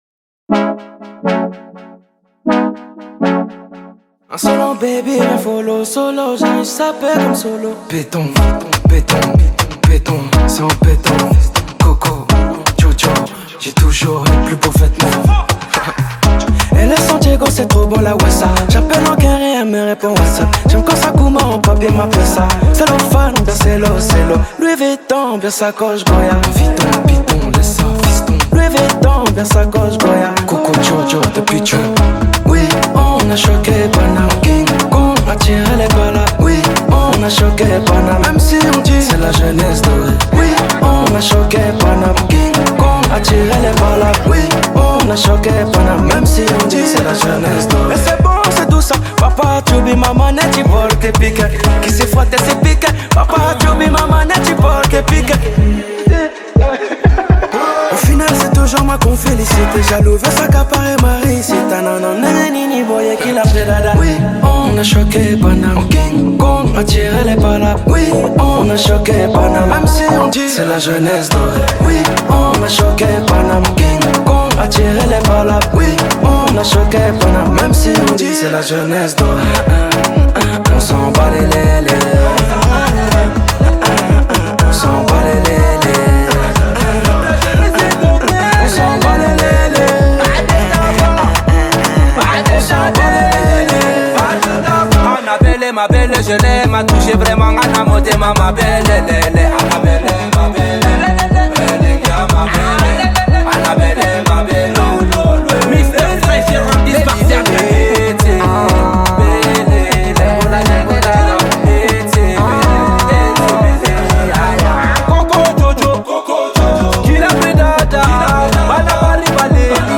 french rap Télécharger